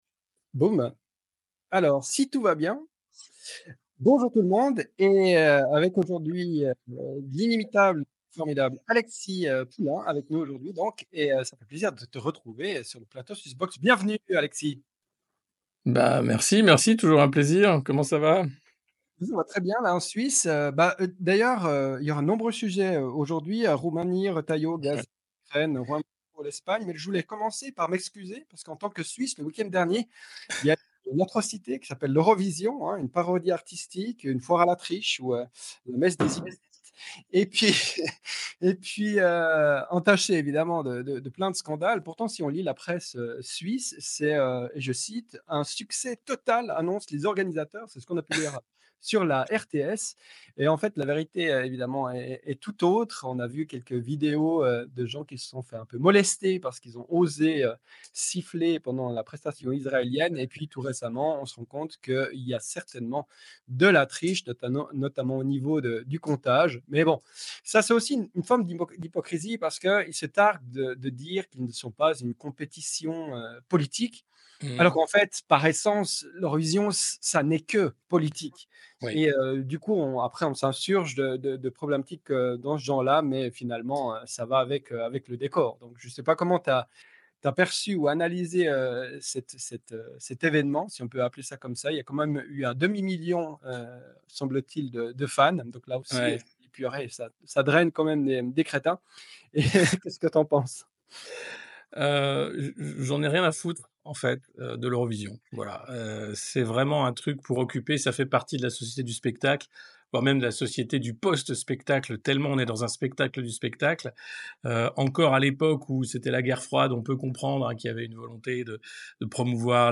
Dans ce live, il partage son analyse des tensions qui traversent l’Occident et le monde.